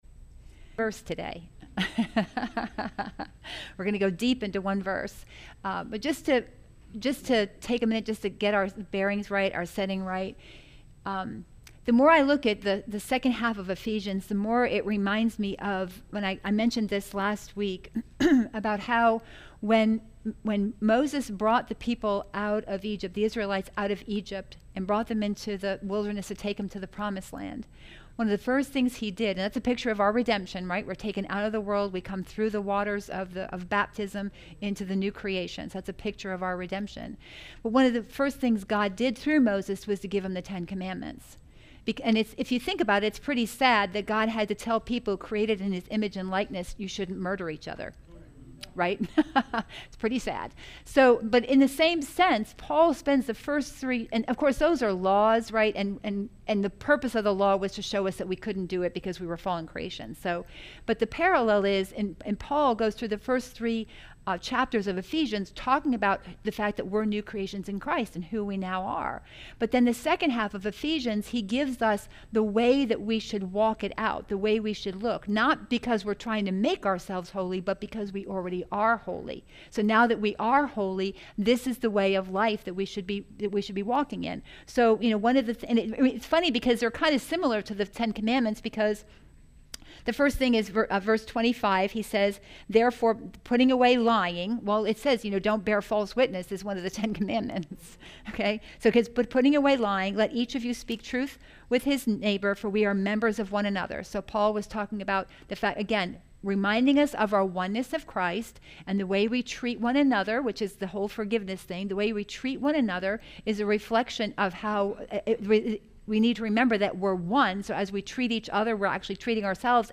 Messages | Kingdom Life Church International